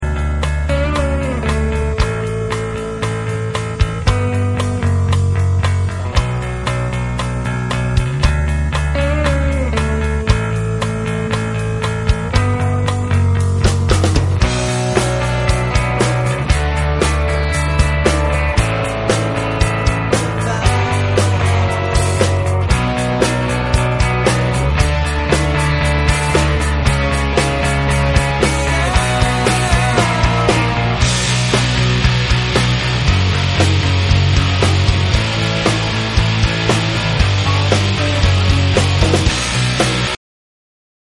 Karaoke lyrics and music will appear on your screen.